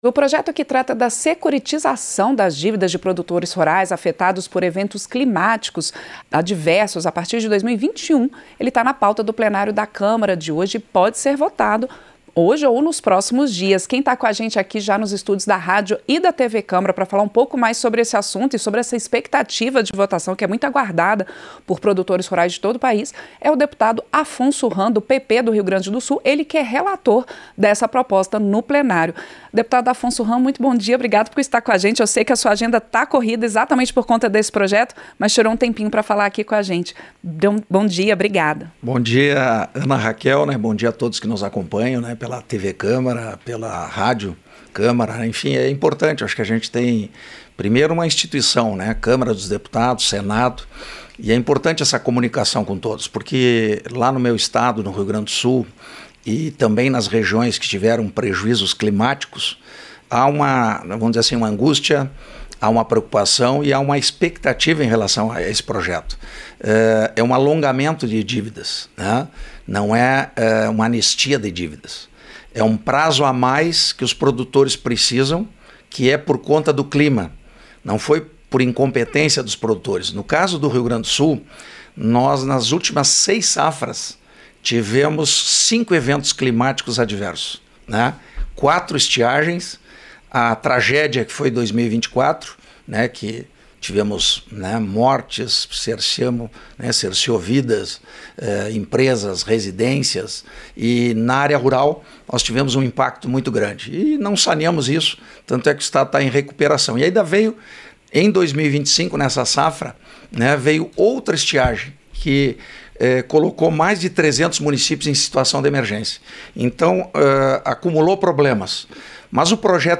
Entrevista - Dep. Afonso Hamm (PP-RS)